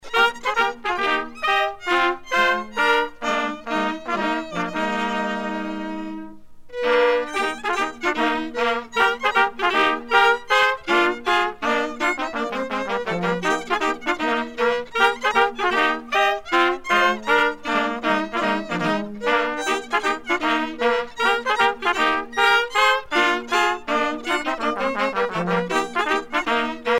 danse : polka
groupe folklorique
Pièce musicale éditée